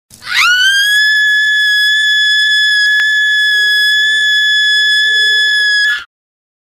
S – SCREAM – A – LONG
S-SCREAM-A-LONG.mp3